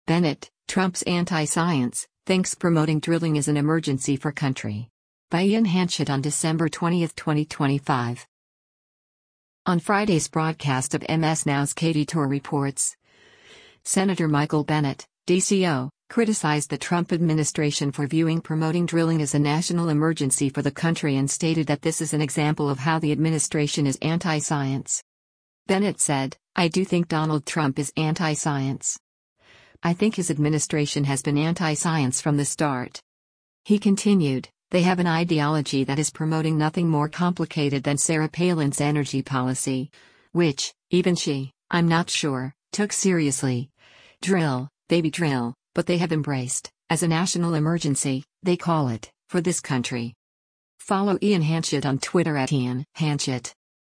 On Friday’s broadcast of MS NOW’s “Katy Tur Reports,” Sen. Michael Bennet (D-CO) criticized the Trump administration for viewing promoting drilling “as a national emergency” for the country and stated that this is an example of how the administration is “anti-science.”